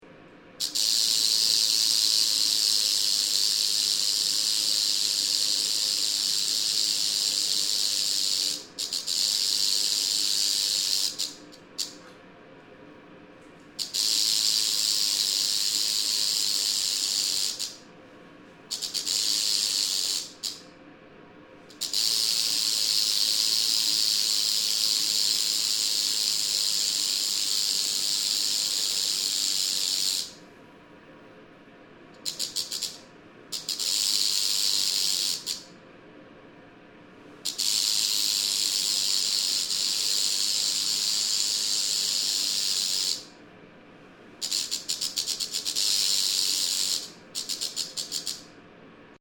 Indoor recording of the rattling of a captive adult Black-tailed Rattlesnake (courtesy of the Arizona-Sonora Desert Museum.)